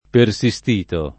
persistere [ per S&S tere ]